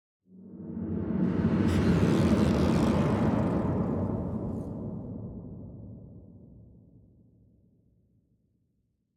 Minecraft Version Minecraft Version 1.21.5 Latest Release | Latest Snapshot 1.21.5 / assets / minecraft / sounds / ambient / nether / crimson_forest / mood1.ogg Compare With Compare With Latest Release | Latest Snapshot